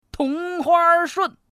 Index of /qixiGame/test/guanDan/goldGame_bak/assets/res/zhuandan/sound/woman/